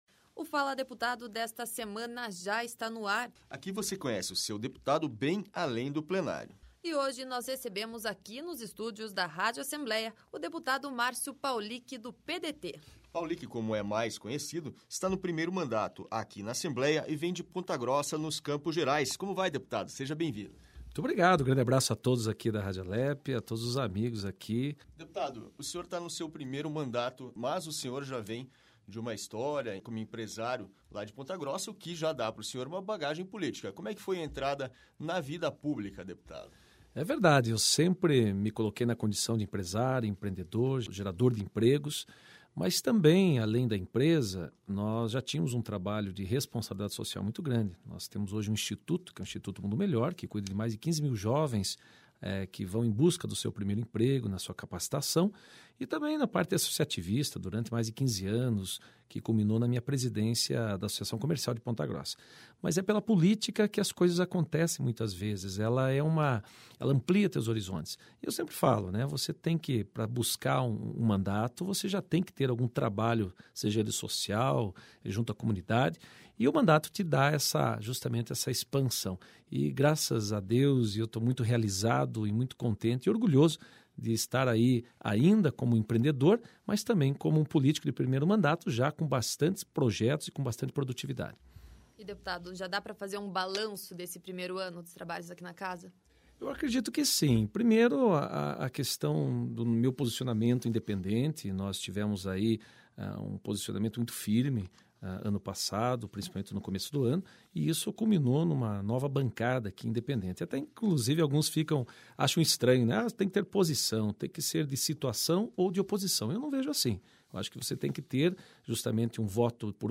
Mas, mesmo estando menos aventureiro, o entrevistado desta semana do “Fala Deputado” incentiva as filhas, por exemplo, a saltar de paraquedas como ele já fez muitas vezes.